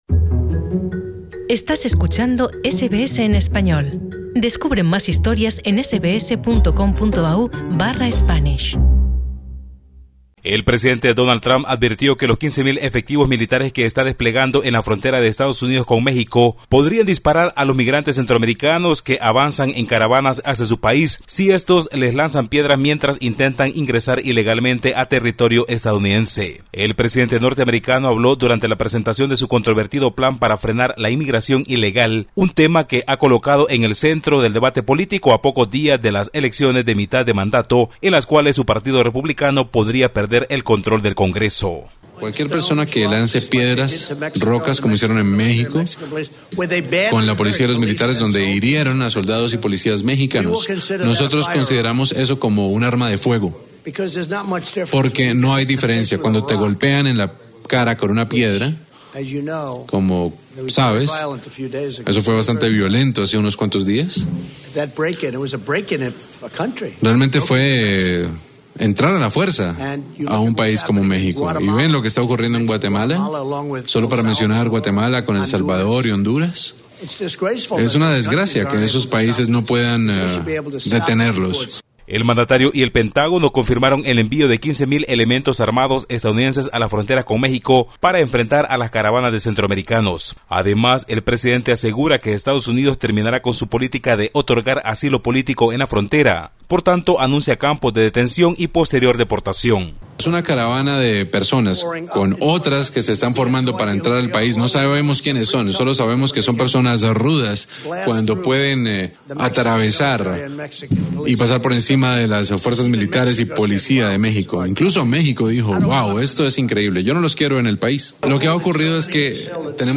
En tanto, Naciones Unidas y activistas pro-derechos humanos critican la militarización de la frontera entre México y Estados Unidos, mientras los centroamericanos aseguran que avanzarán. Esucha el informe de nuestro corresponsal en la región